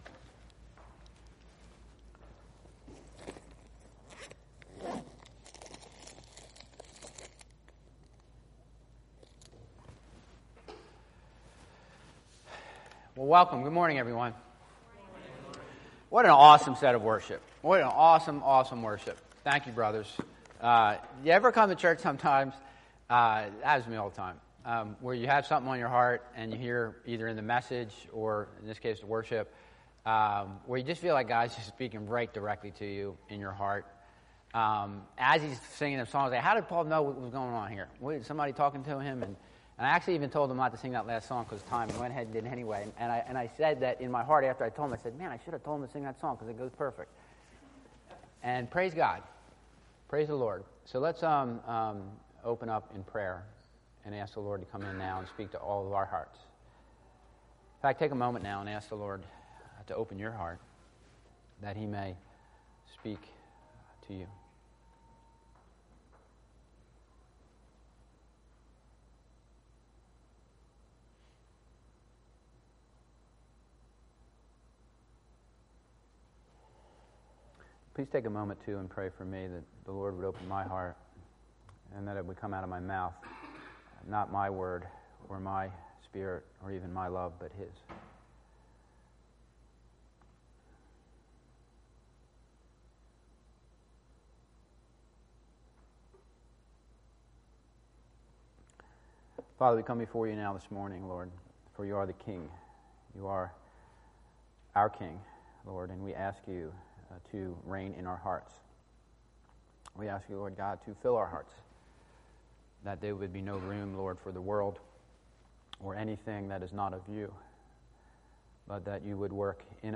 The King of Hearts | CBCWLA English Congregation